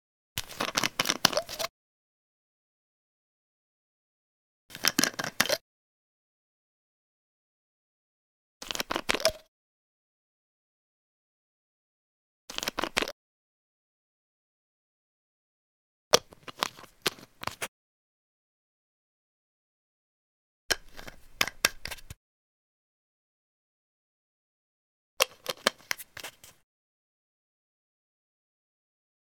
Plastic Bottle Remove Twist Top Lid Sound
household